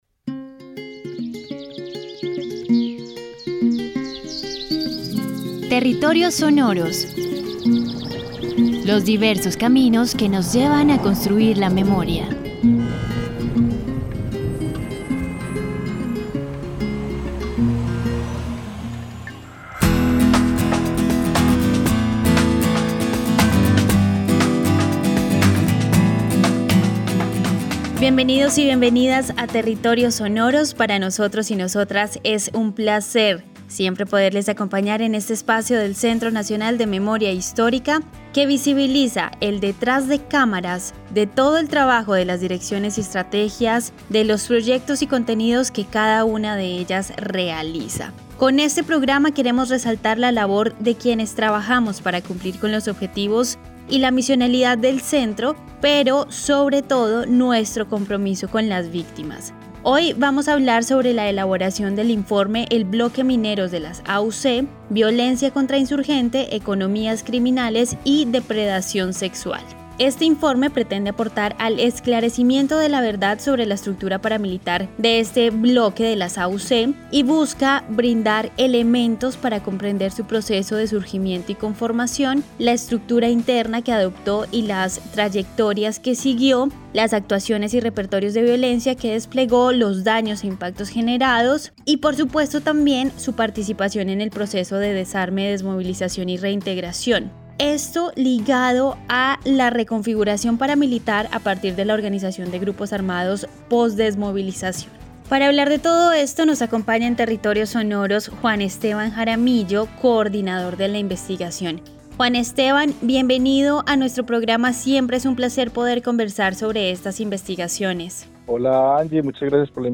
Conversación sobre el Bloque Mineros.